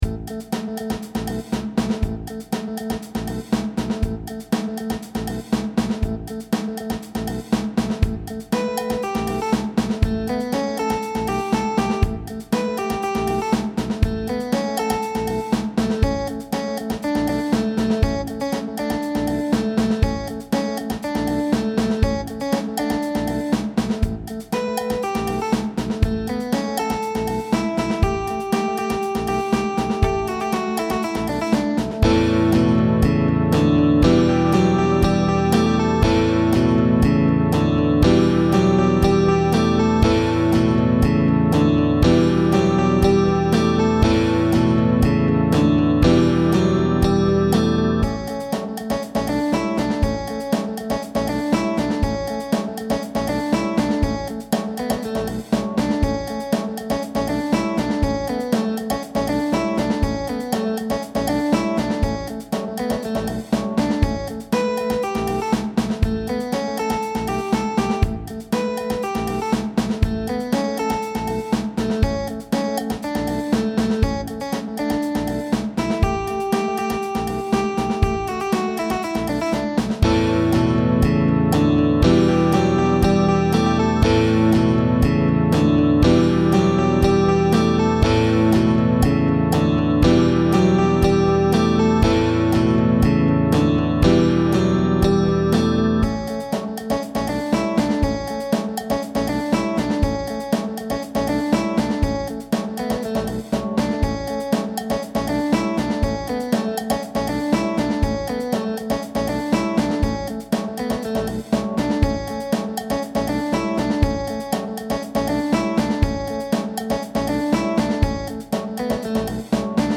On this file the first chorus is only half the length.